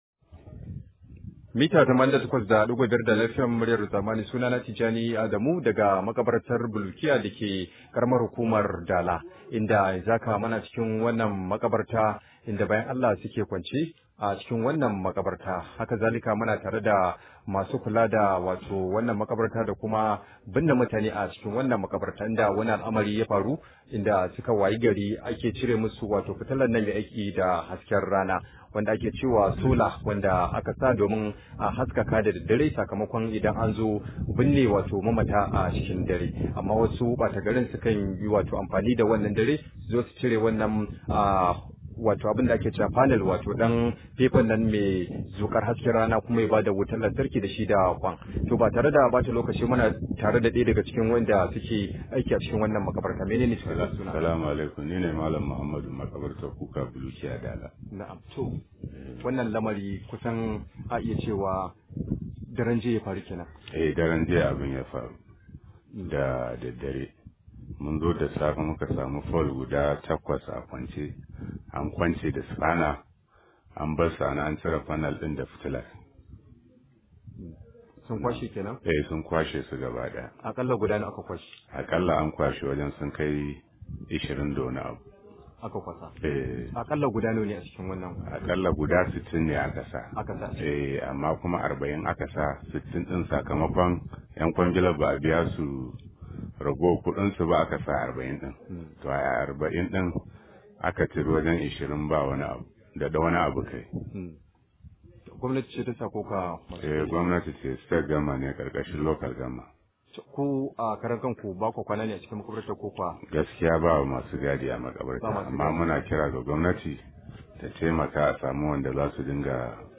Rahoto: Mu na fama da satar mana Fitilun Makabarta – Al’ummar Bulukiya